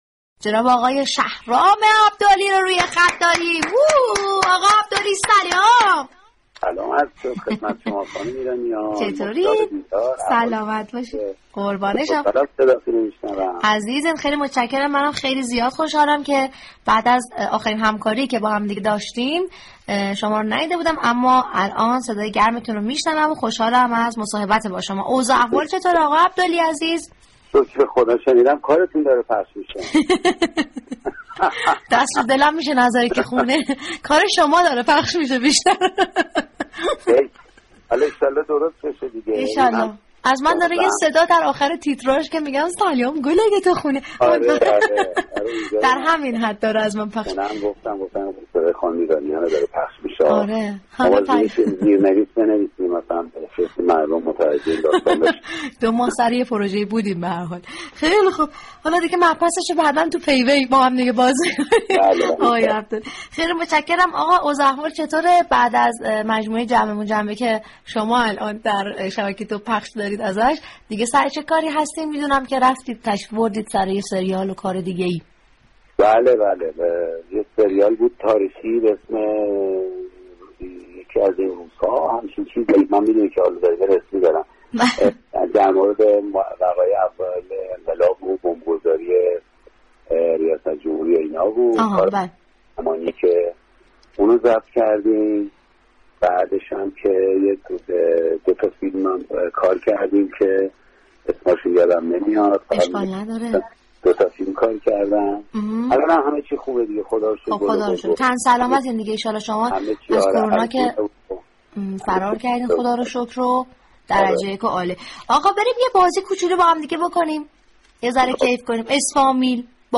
رادیو صبا در برنامه همپای صبا میزبان شهرام عبدلی بازیگرسینما و تلویزیون شد
به گزارش روابط عمومی رادیو صبا، برنامه زنده «همپای صبا» هر روز با سفر به شهرهای مختلف ایران مخاطبان را با آداب و رسوم فرهنگ، مشاهیر و مفاخر سرزمینمان آشنا می كند.
قطار صبا در این برنامه روز یكشنبه سوم بهمن در ایستگاه هنرمندان همسفر شهرام عبدلی بازیگر خوب سینما و تلویزیون شد و با وی گفتگوی صمیمی داشت.